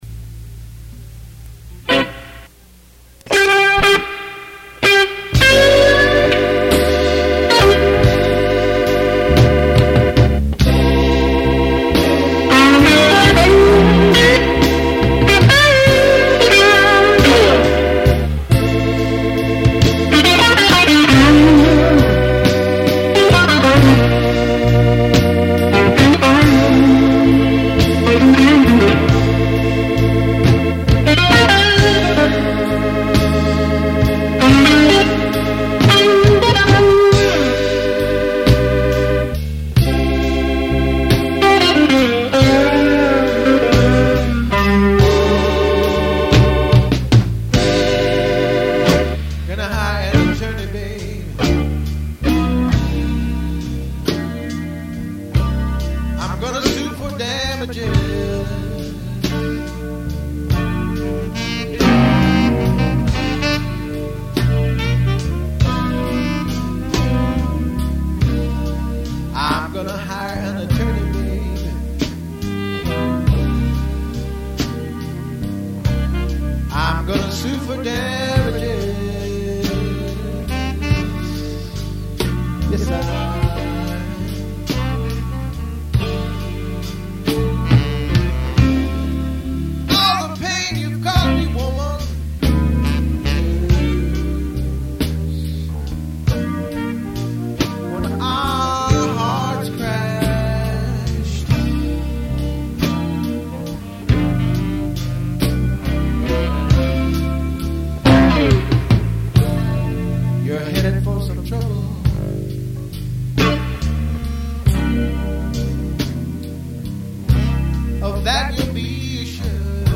Great Guitar Work!!!!